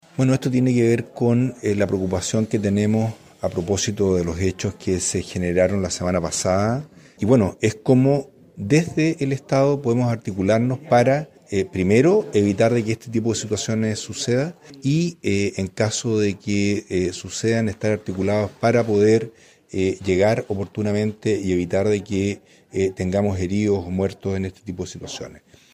En una reunión intersectorial, el delegado presidencial de Chiloé, Marcelo Malagueño, destacó que el objetivo es articular acciones para prevenir futuros incidentes.